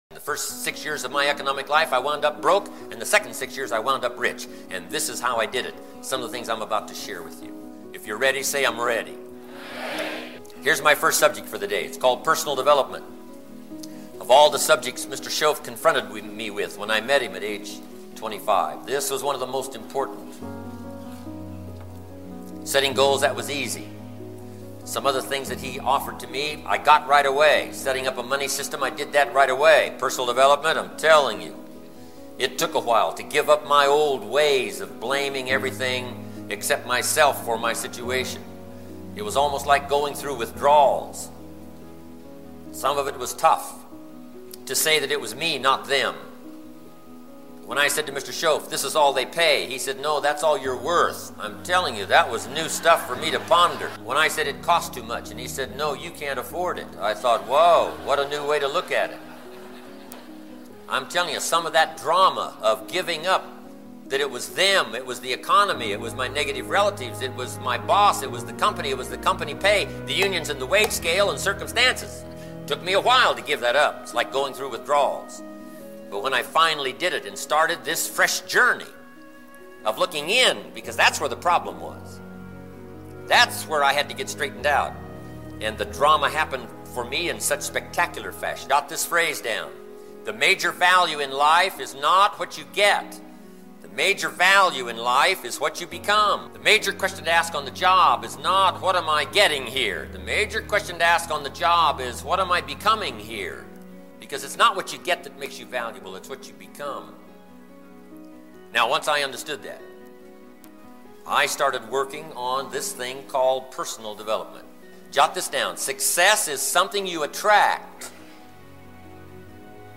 Speaker: Jim Rohn